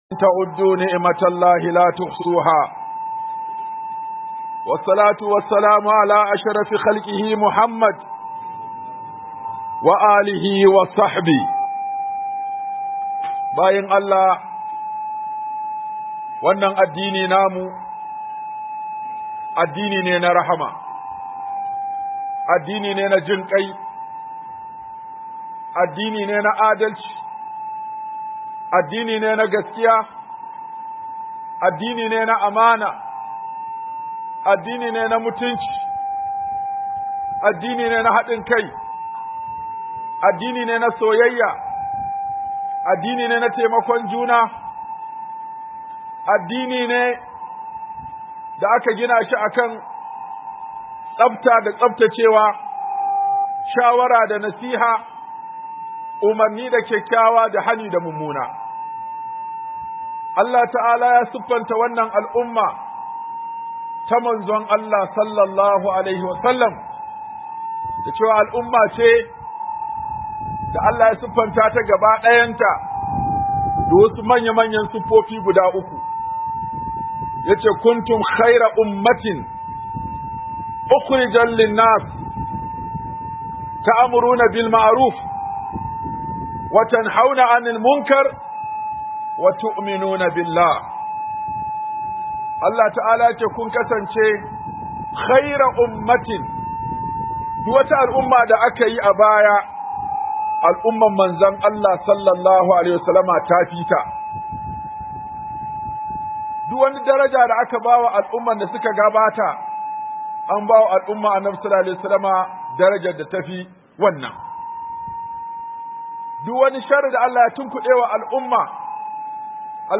Hudhuba Mai Taken Neman Taimakon Allah - Huduba